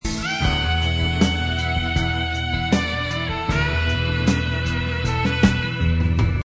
Here the sax figure that began the song now reappears.